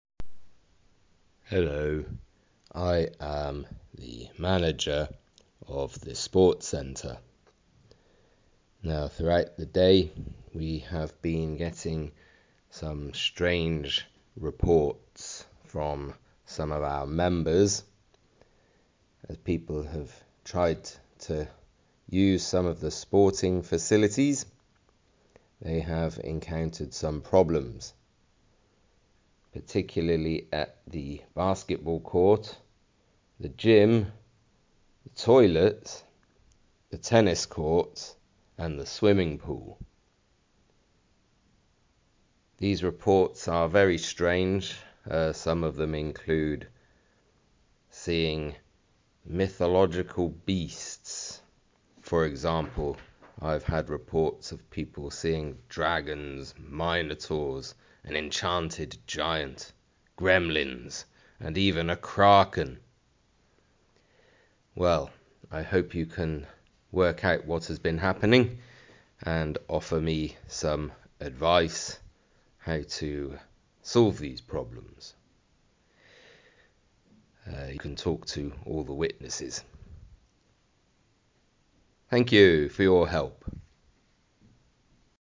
The manager has left you an audio message. Listen to what he says.
sport-centre-manager.mp3